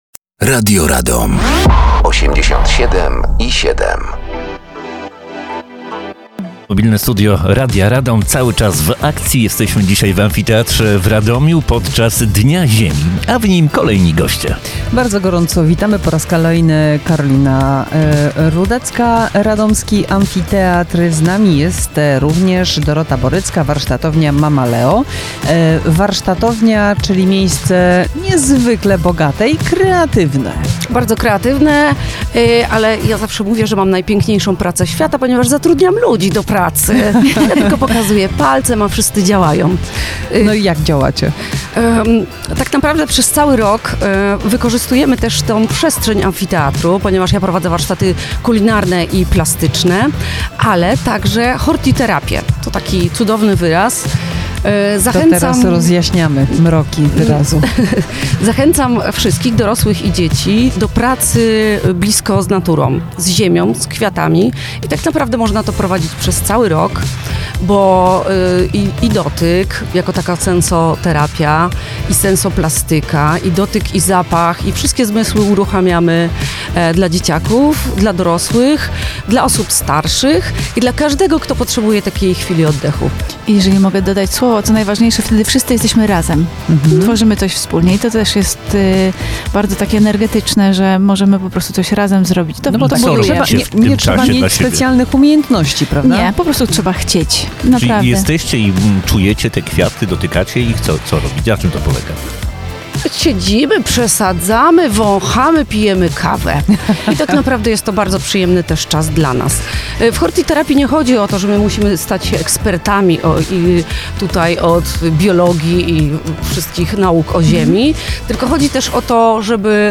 Mobilne Studio Radia Radom dzisiaj ustawiliśmy w Miejskim Ośrodku Kultury Amifoteatr godzie odbywa się Dzień Ziemi w Ogrodzie Społecznym.